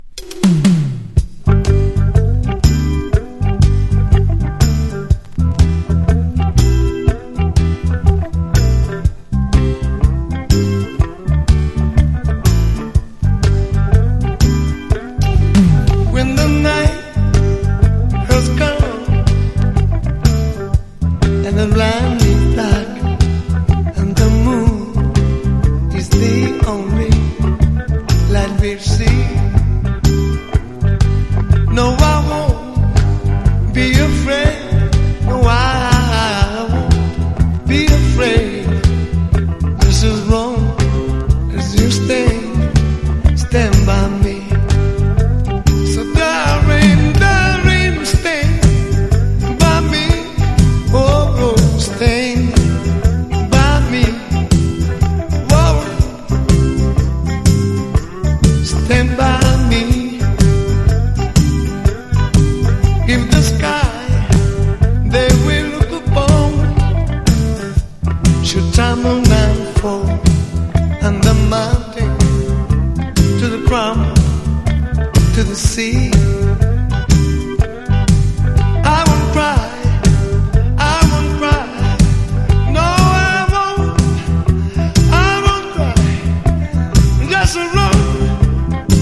CITY POP / AOR# REGGAE / SKA / DUB# 和モノ